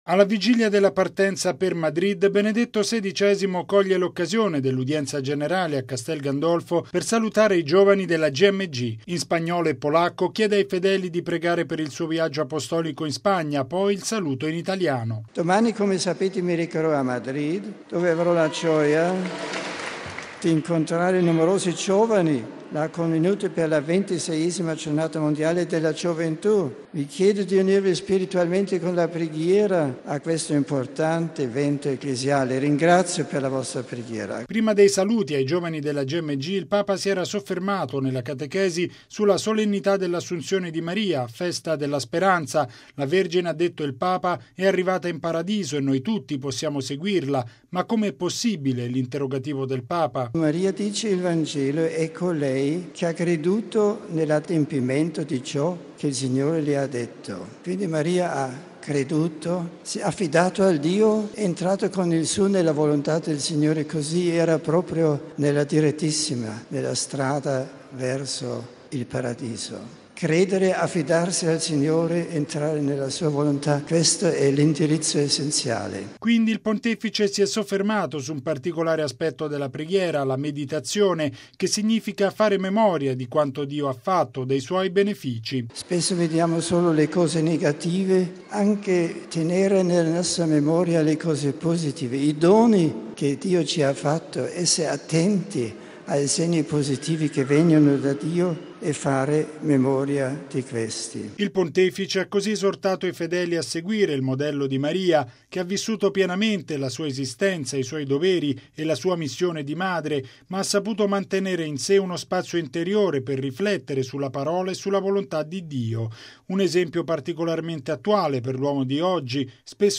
Alla vigilia della partenza per Madrid, Benedetto XVI coglie l’occasione dell’udienza generale a Castel Gandolfo per salutare i giovani della Gmg.
Poi il saluto in italiano: